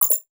Simple Digital Connection 6.wav